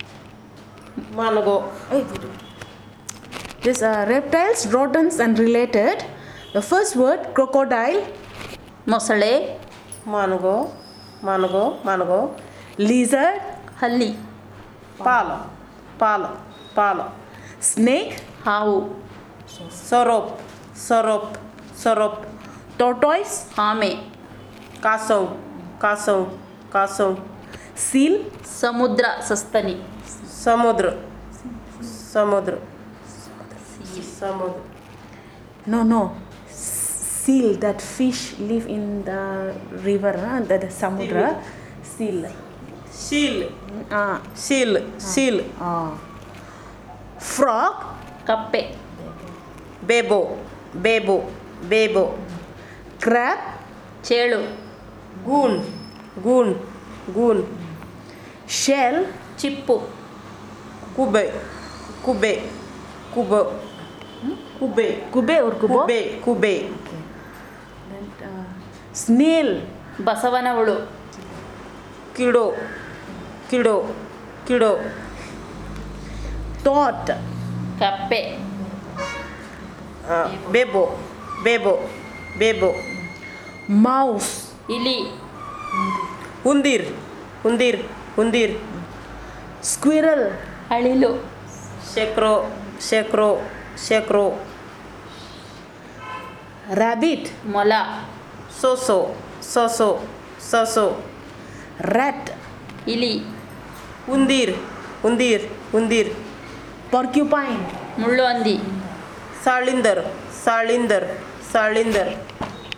NotesThis is an elicitation of words about reptiles, rodents, and associated subjects using the SPPEL Language Documentation Handbook.